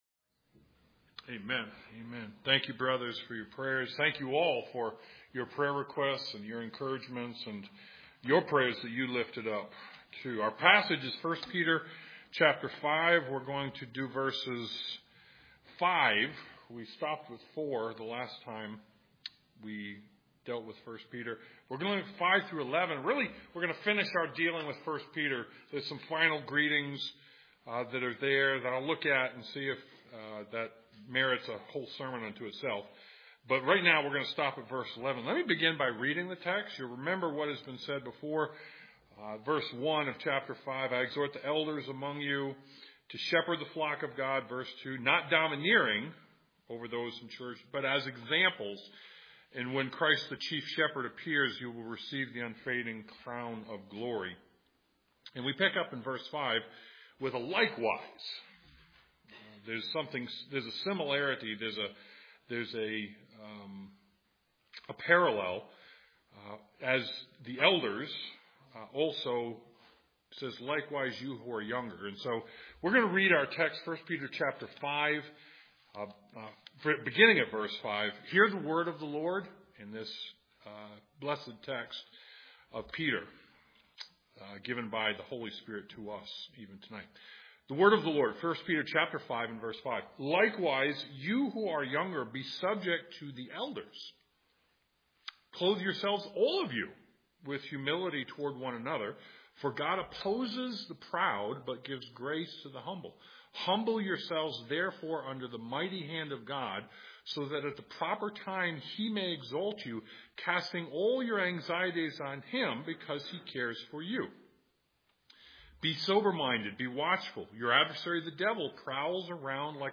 1 Peter 5:5-11 Service Type: Sunday Evening 1 Peter 5:5-11 Given God’s power and promises